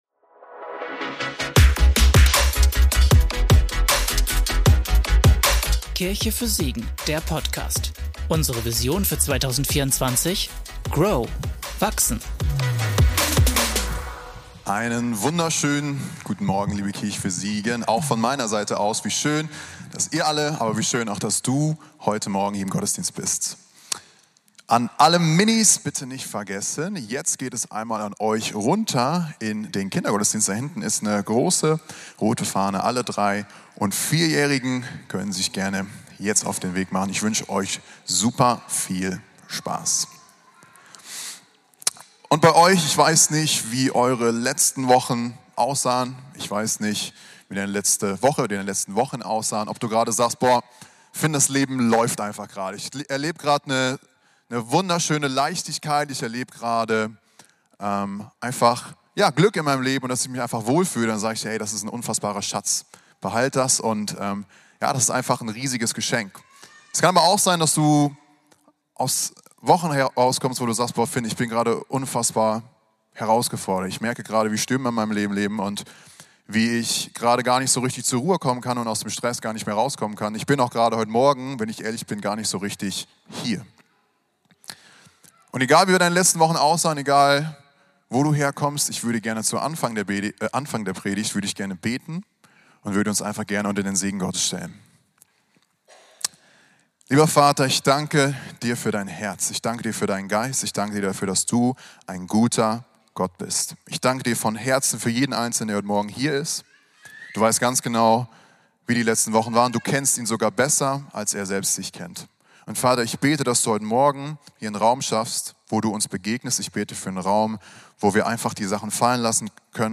Wer spricht da in meinem Kopf? - Predigtpodcast